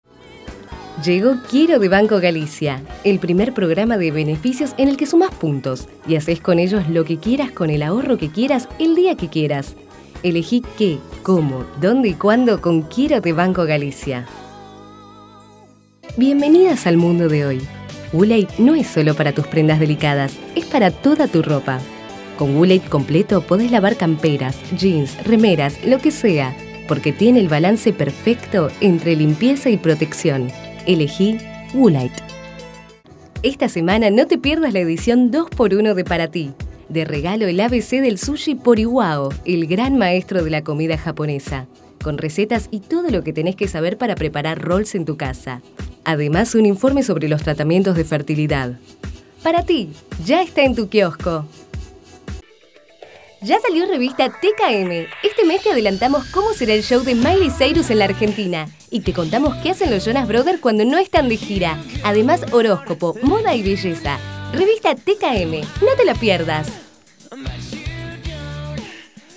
Locutora nacional
Kein Dialekt
Sprechprobe: eLearning (Muttersprache):